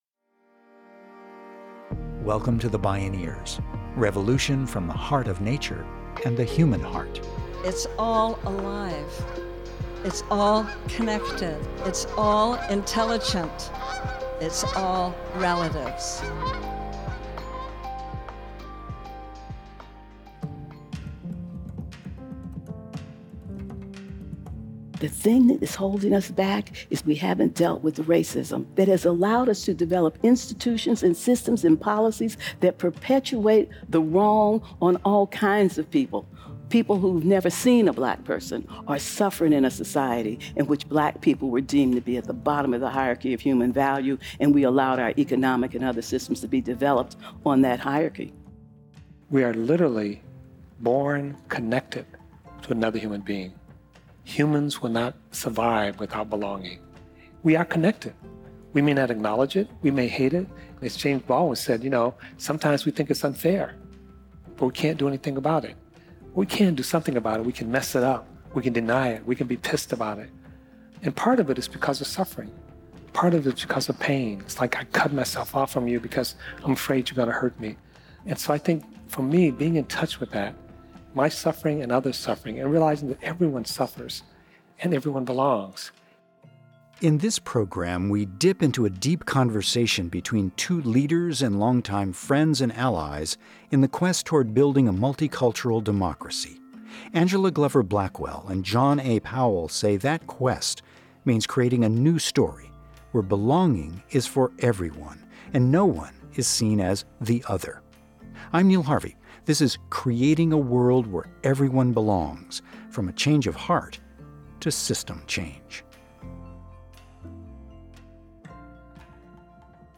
A deep conversation between Angela Glover Blackwell and john a. powell, two long-time friends and leaders in a quest toward building a multicultural democracy.